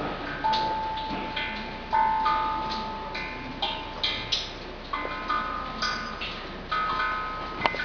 水琴窟
「地中に埋めた水を張った瓶に手水鉢の水が滴り落ち、水音が反響して音をつくりだすもの」だそうです。乾季には水量が減って聞けないこともあるそうですが、この日は十分聞くことが出来ました。